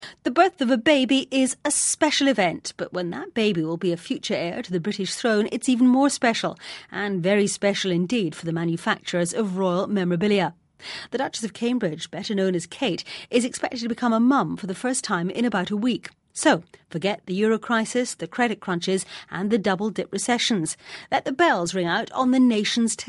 【英音模仿秀】精明商家蠢蠢欲动 听力文件下载—在线英语听力室